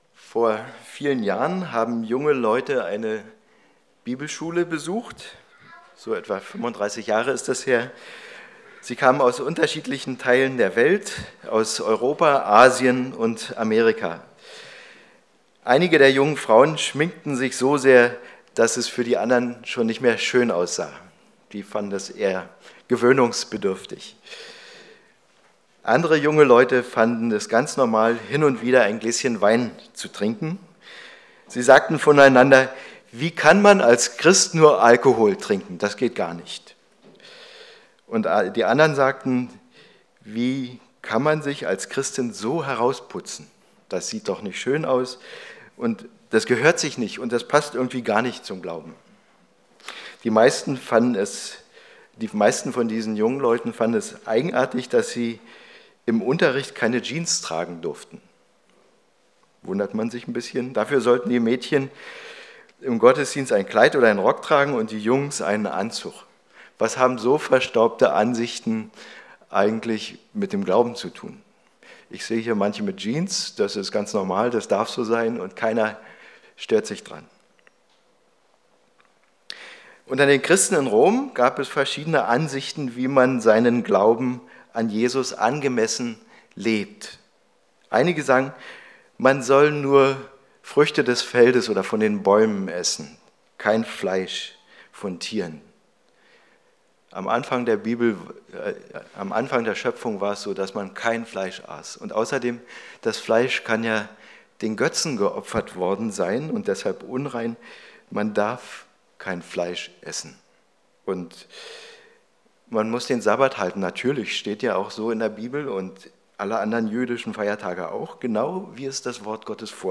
Hier finden Sie die aktuellen Predigten zum Nachhören.